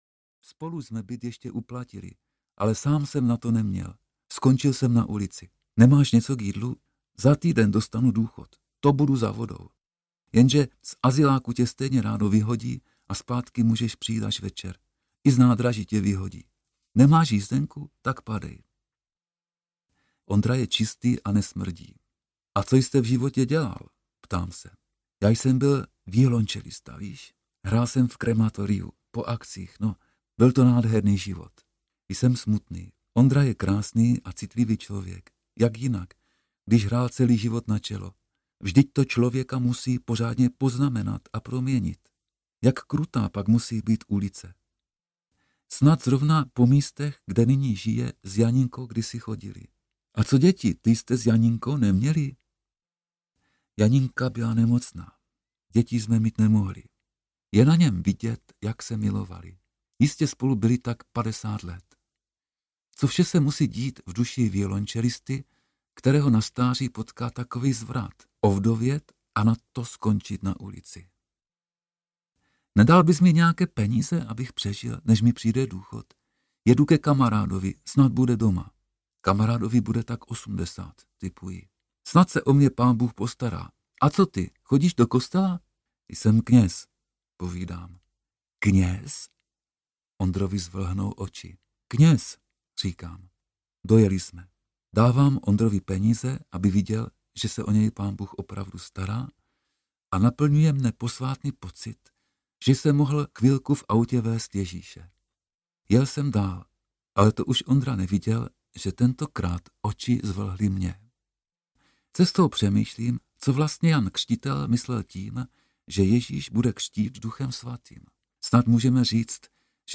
Stopařem na této zemi audiokniha
Ukázka z knihy
• InterpretLadislav Heryán